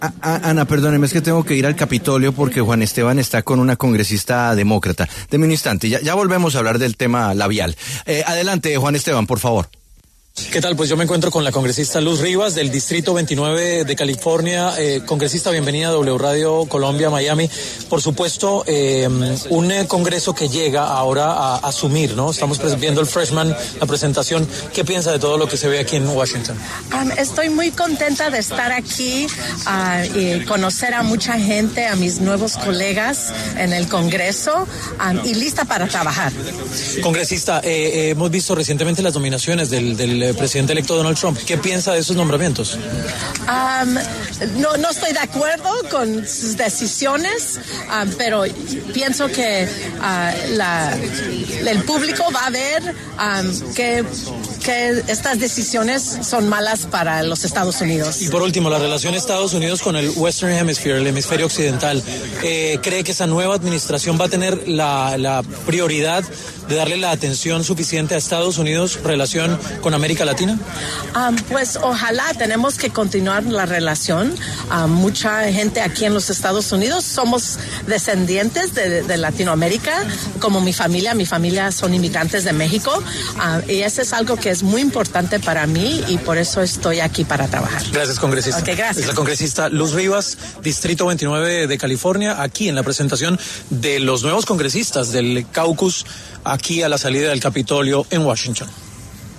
En conversación con La W, Luz Rivas, congresista demócrata electa por California, habló sobre los nombramientos que ha hecho el presidente electo de Estados Unidos, Donald Trump, en los últimos días.